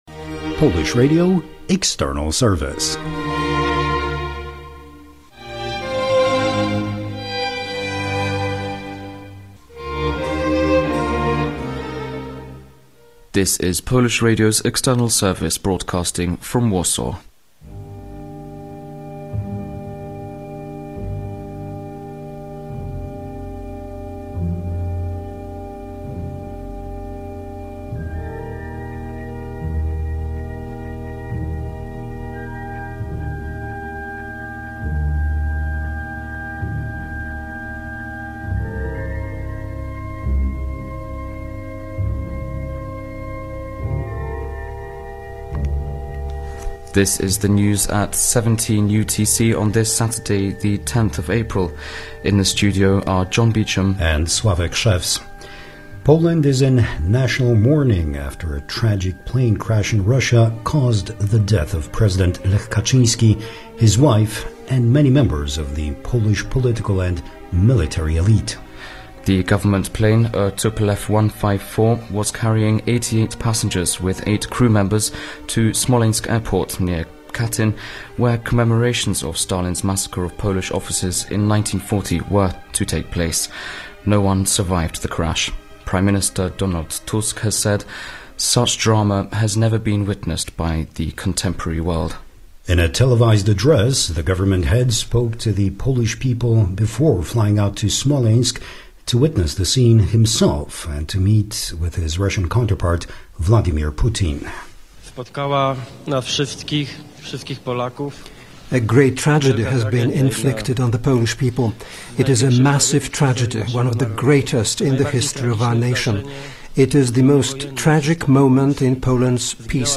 Grief Comes To Smolensk - Poland Loses A President - April 10, 2010 - news from the External Service of Radio Poland.